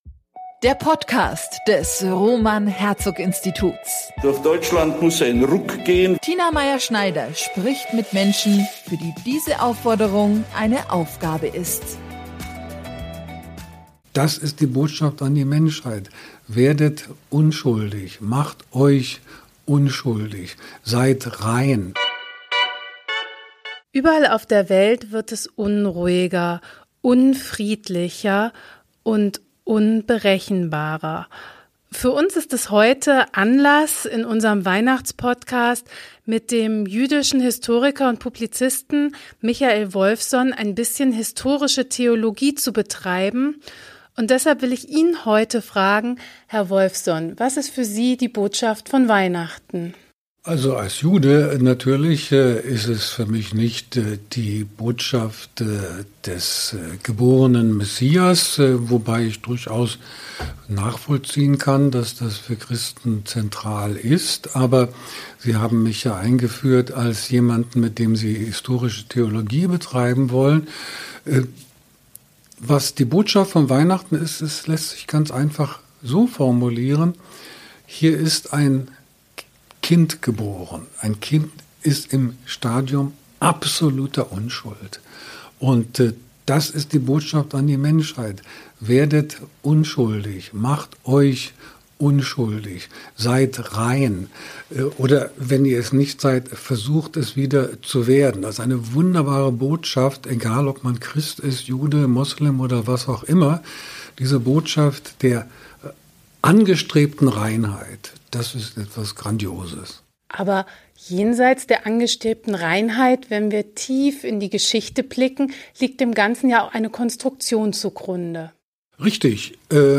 Weihnachtliche Grüße aus dem Roman Herzog Institut mit Prof.